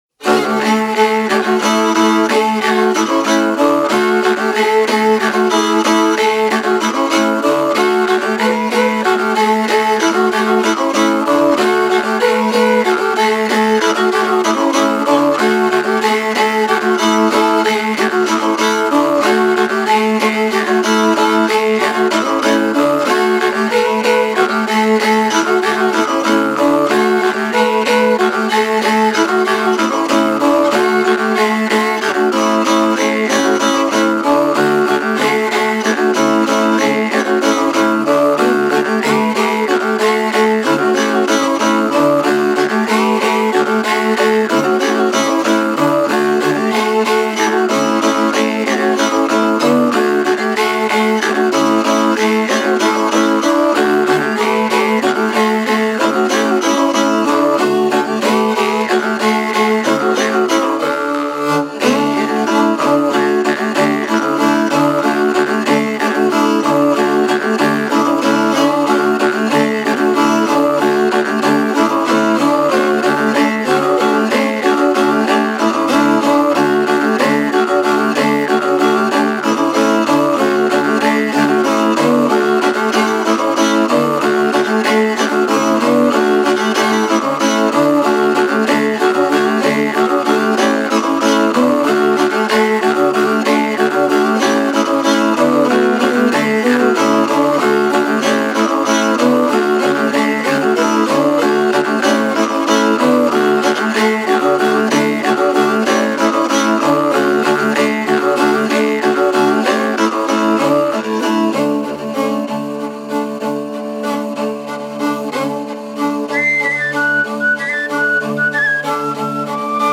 Labajalg nr 1 (nimetus teadmata)